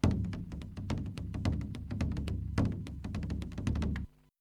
The Bodhrán is an Irish frame drum with deep roots in Irish and Australian Bush music.
This versatile instrument is played by holding the drum in one hand and striking the drum’s skin with a small double-headed stick, often referred to as a “tipper.”
Bodhrain-Track-19.wav